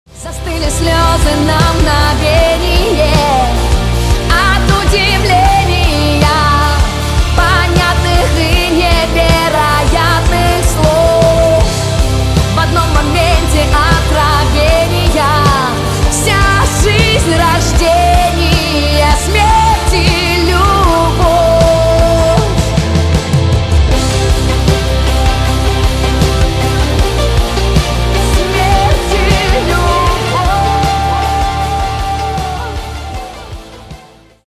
Рок Металл Рингтоны